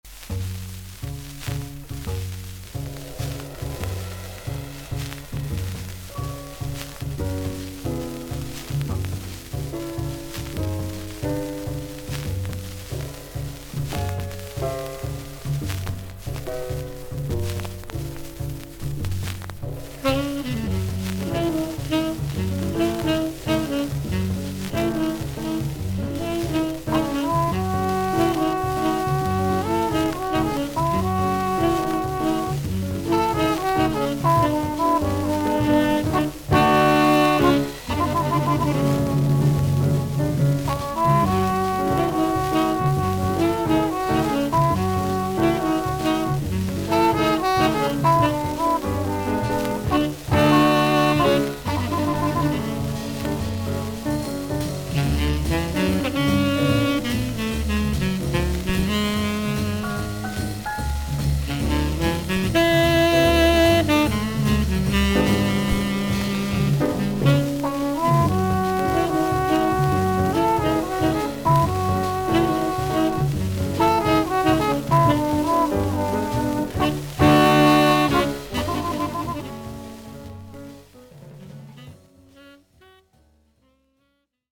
少々軽いパチノイズの箇所あり。全体的にサーフィス・ノイズあり。